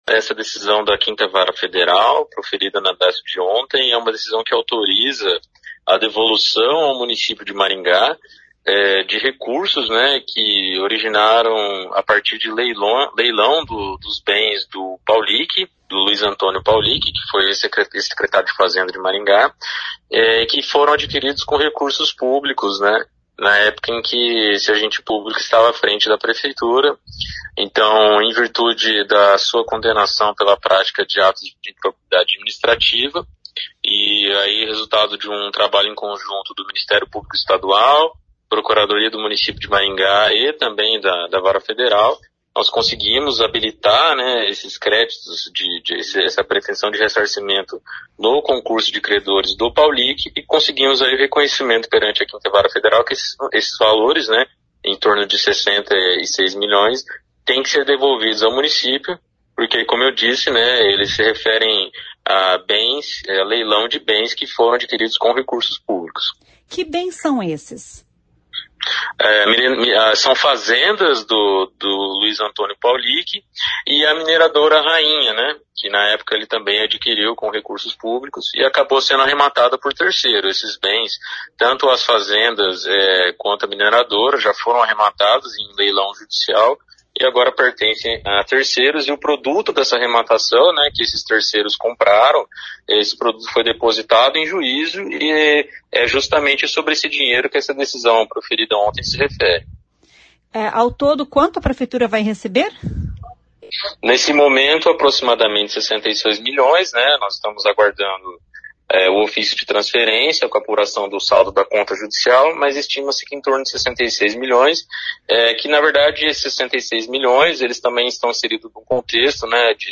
Ouça o que diz o procurador do Município, Luiz Fernando Boldo: